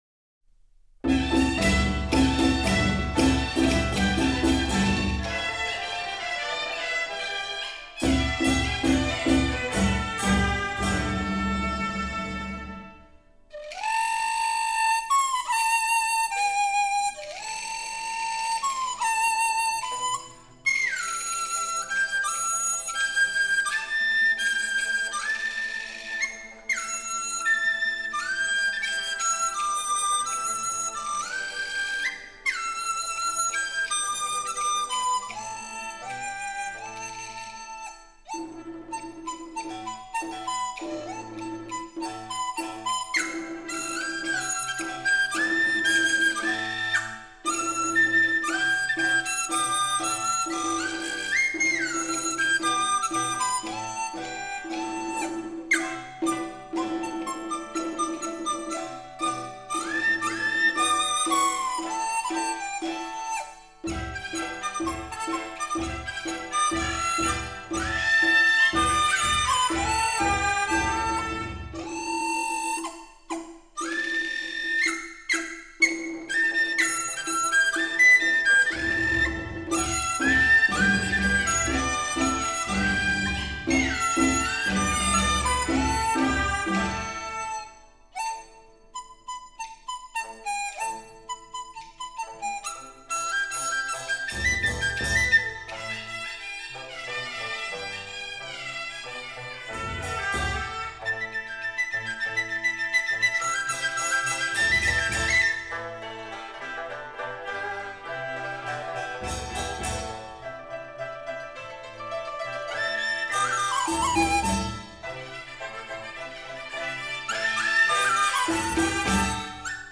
中国吹管乐
梆笛